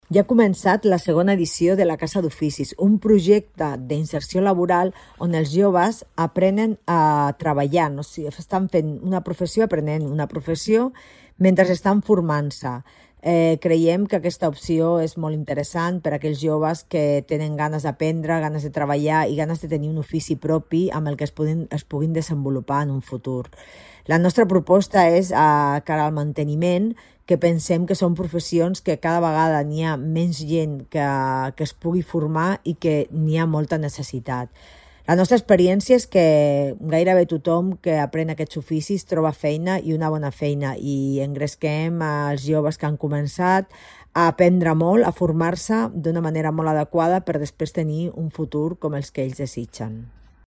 Rosa Cadenas, regidora Promoció Econòmica